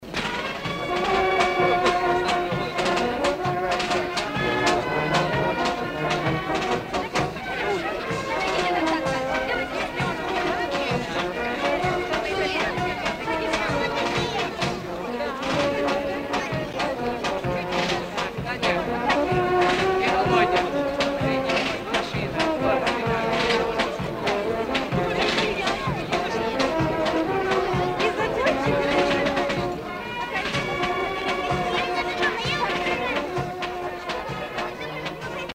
ув. знатоки помогите пожалуйста опознать МАРШ !!!